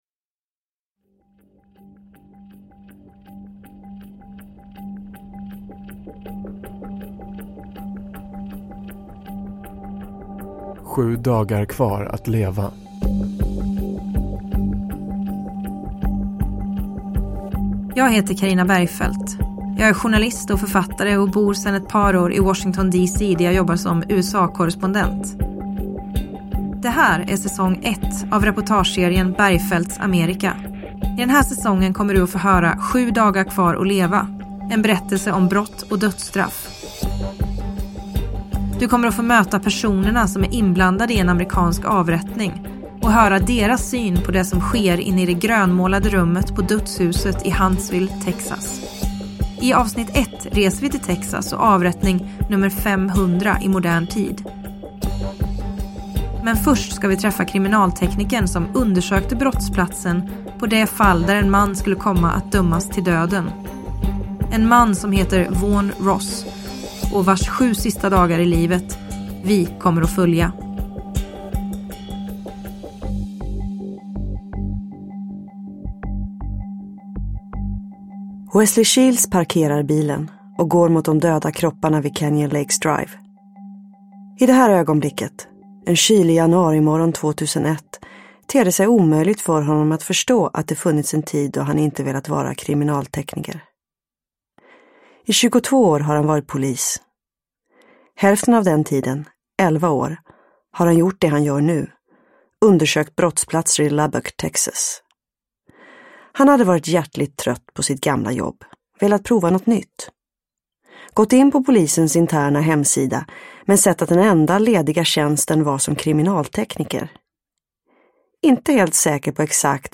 Bergfeldts Amerika. S1A1, Sju dagar kvar att leva – Ljudbok
Typ Nedladdningsbar ljudbok